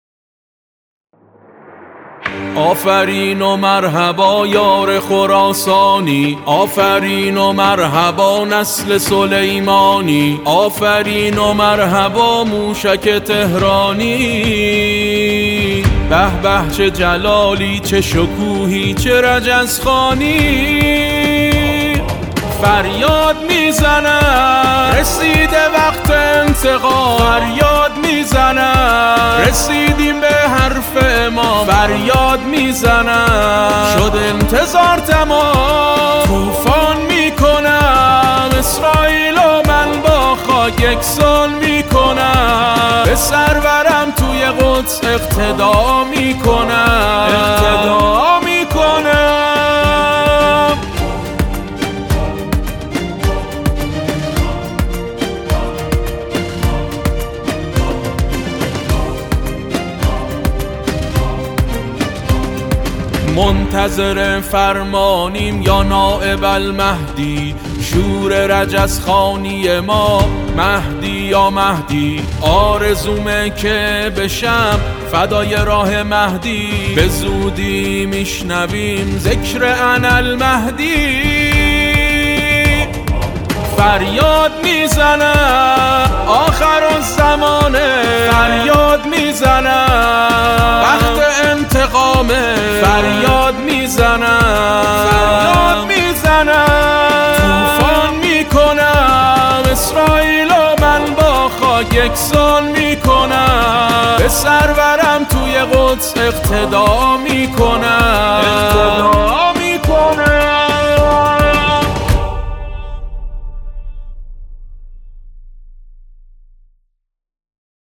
قطعه جدید وحماسی